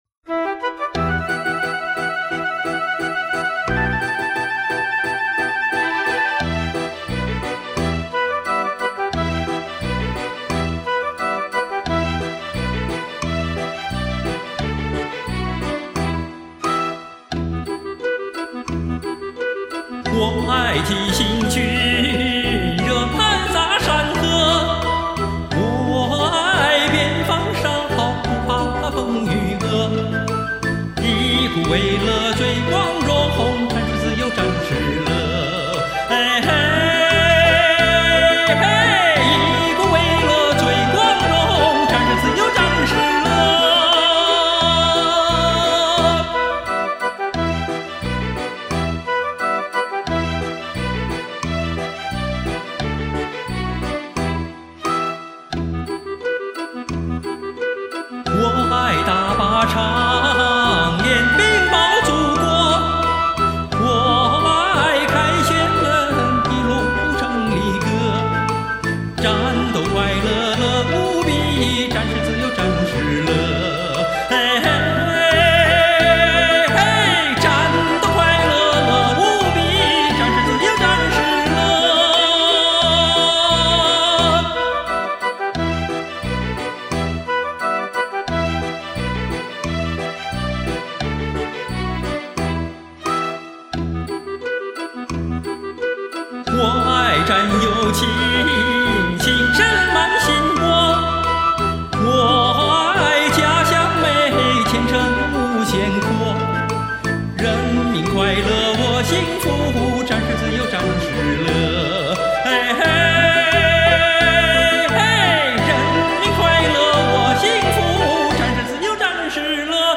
当兵的节日到了,给大家献上一首轻松愉快的军旅歌曲,希望大家喜欢!